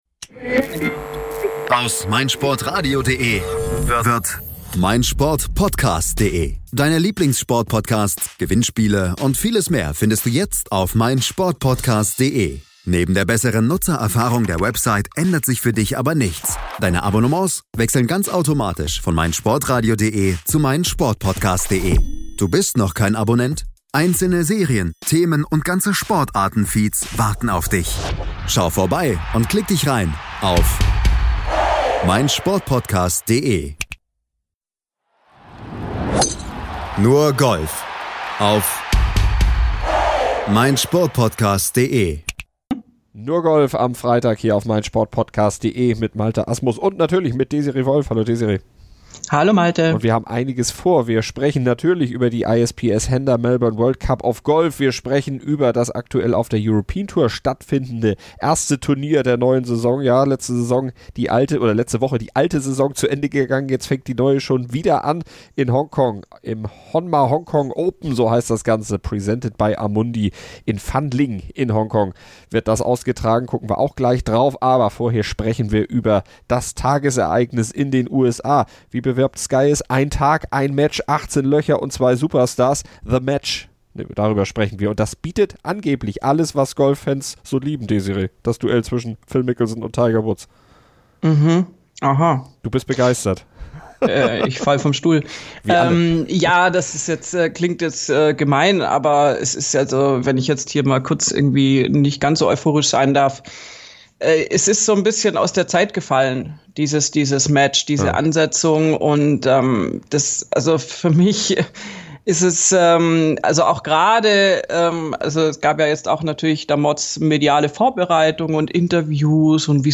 Und sie kriegen akustische Argumentationsgilfe von Rory McIlroy und Tommy Fleetwood, die ebenfalls nicht viel Lust auf The Match haben.